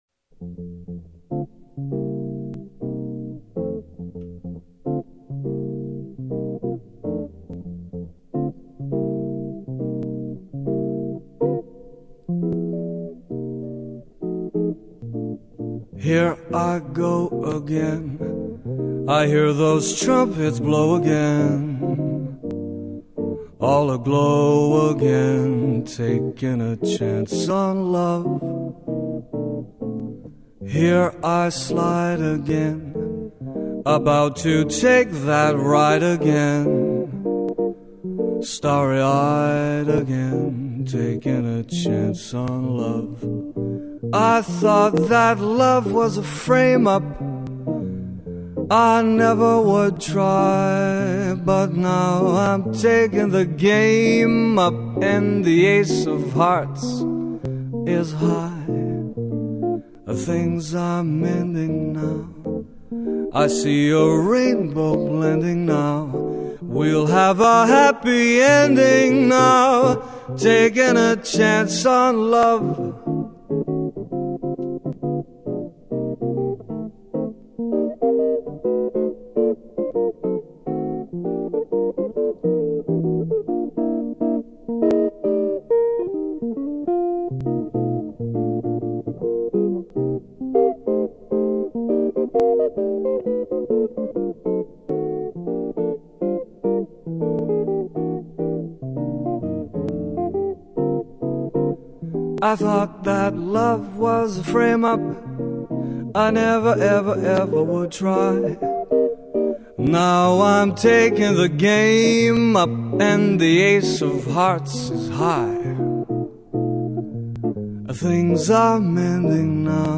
as a duo.